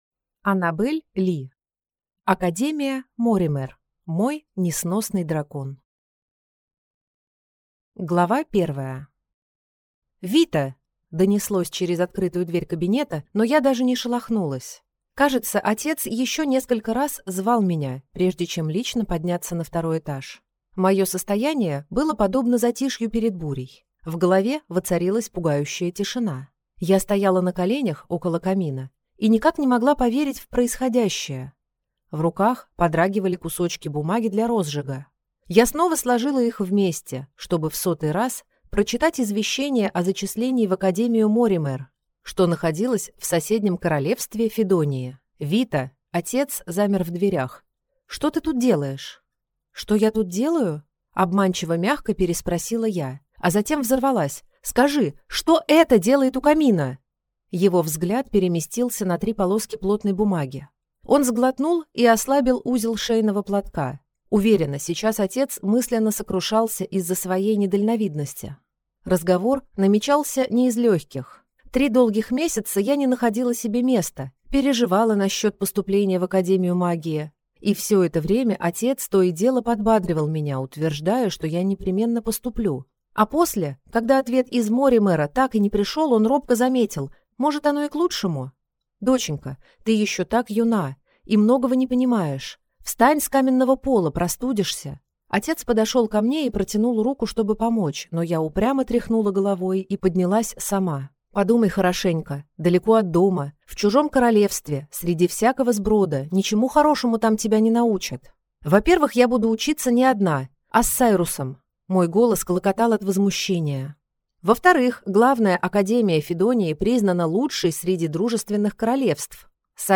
Аудиокнига Академия Моримэр. Мой несносный дракон | Библиотека аудиокниг